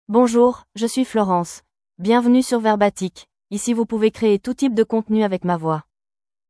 FemaleFrench (France)
FlorenceFemale French AI voice
Florence is a female AI voice for French (France).
Voice sample
Listen to Florence's female French voice.
Florence delivers clear pronunciation with authentic France French intonation, making your content sound professionally produced.